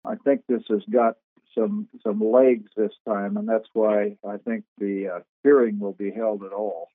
State Rep. Mike Dodson spoke on the issue during KMAN’s weekly legislative update Friday morning.